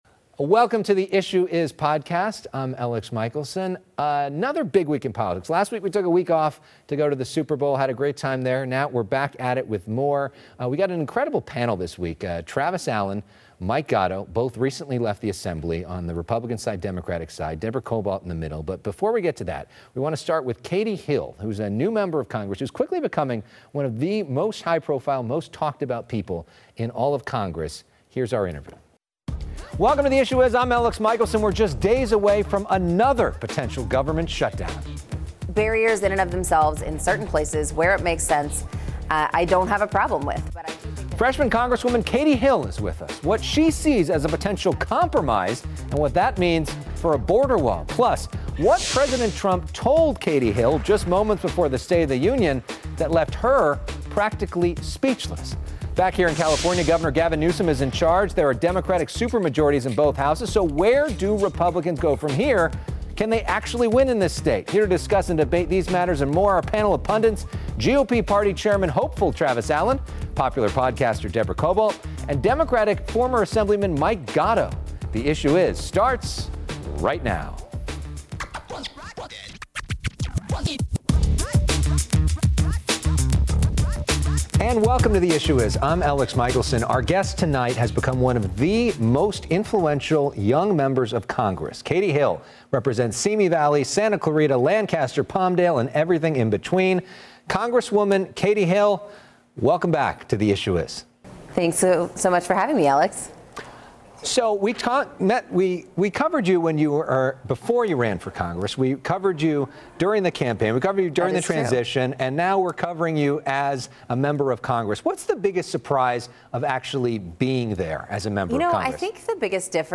We're just days away from another potential government shutdown. Freshman Congresswoman Katie Hill is with us on this episode of "The Issue Is:" - What she sees as a potential compromise and what that means for a border wall.